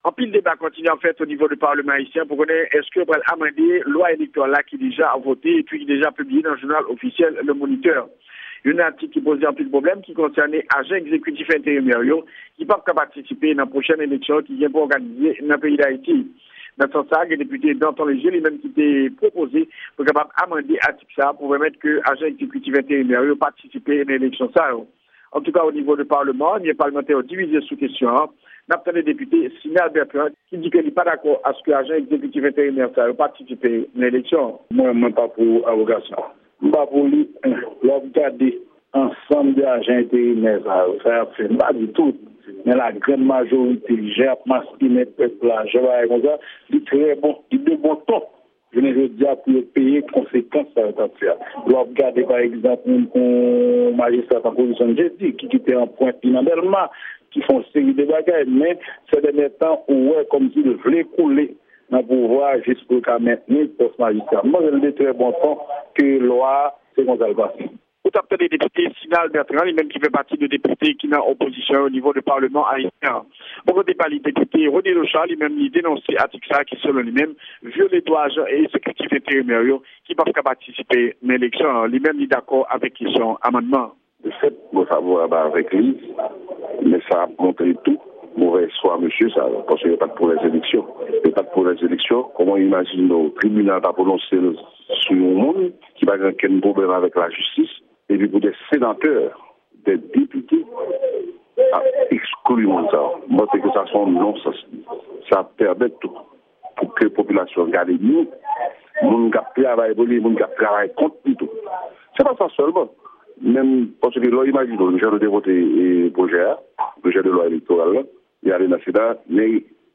Yon repòtaj